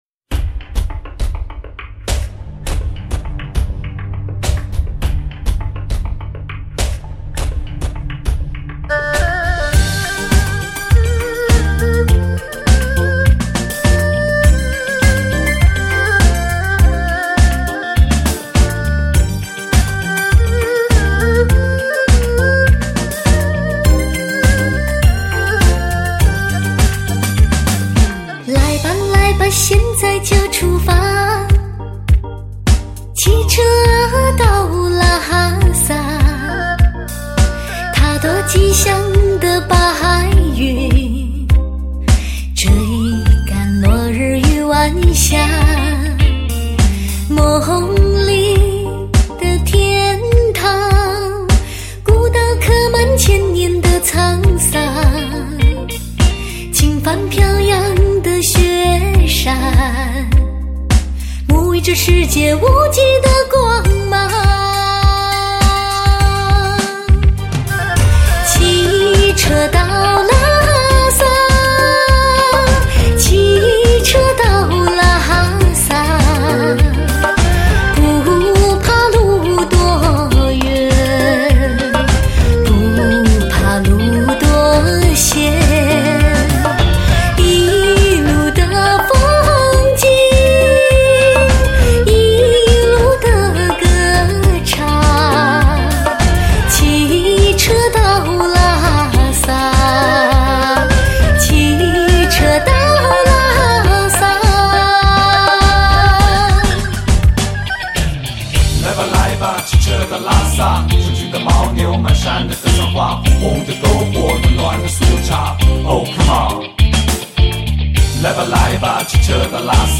为低音质MP3